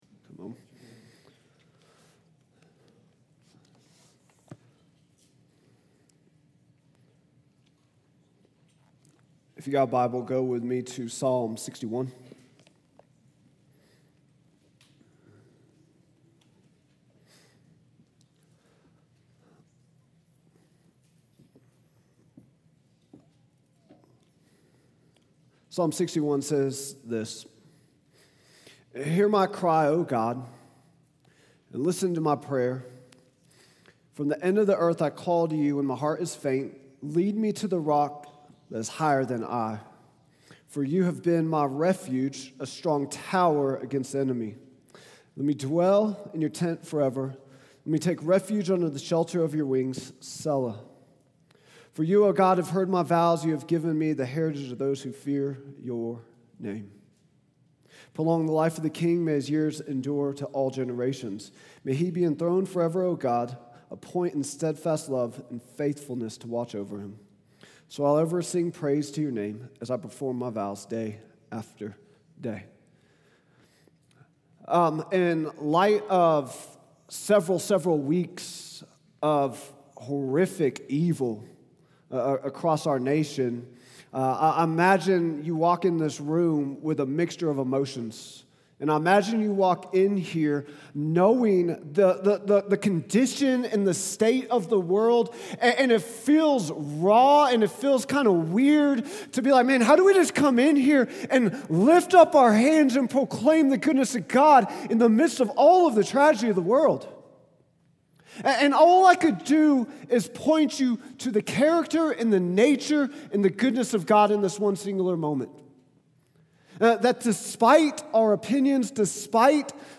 Vision & Values Meet Our Team Statement of Faith Sermons Contact Us Give Movement Leaders | Acts 7:57-8:3 September 14, 2025